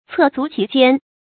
厕足其间 cè zú qí jiān
厕足其间发音